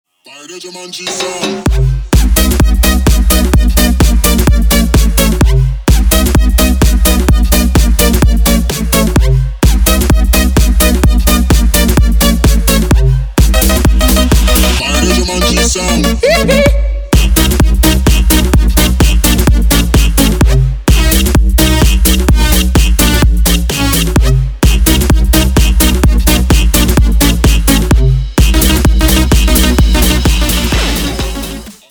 • Качество: 320, Stereo
громкие
энергичные
electro house
озорные